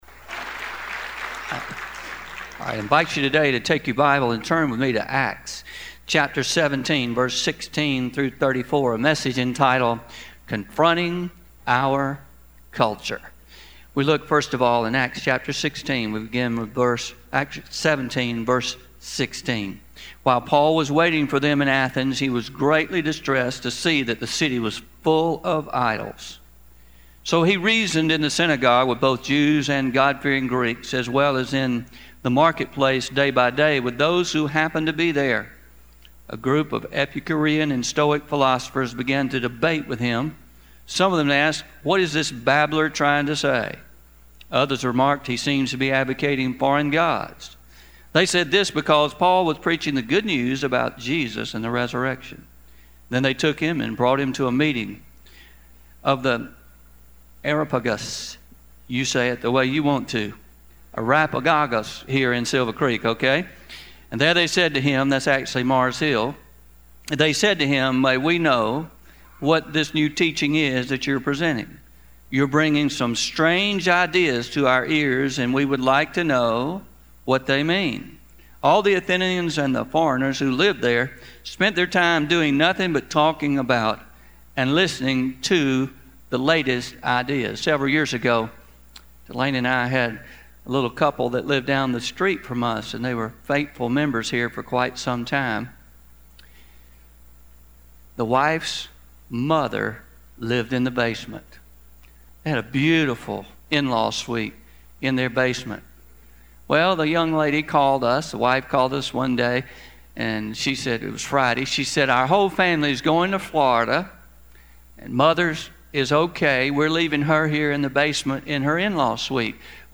Pre-recorded – Confronting Our Culture